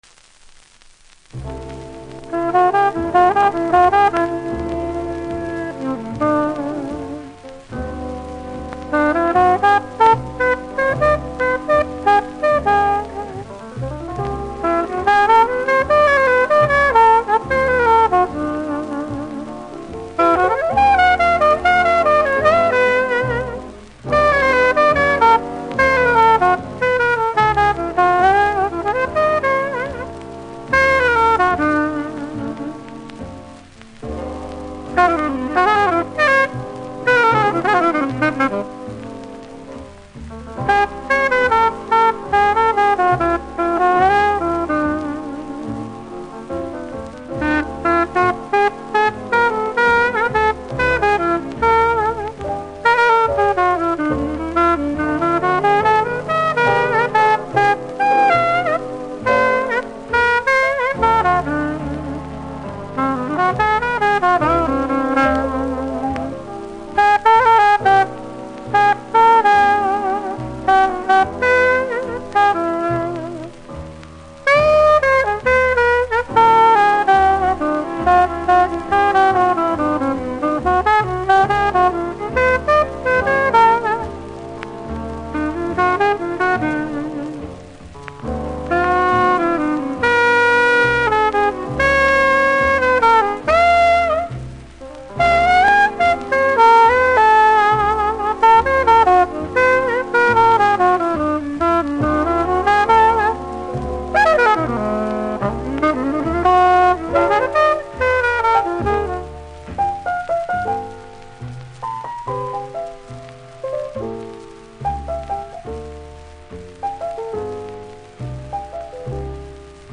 INSTRUMENTAL
普段ジャマイカ盤プレイしている人なら問題無い程度ですが全体的にプレスノイズありますので試聴で確認下さい。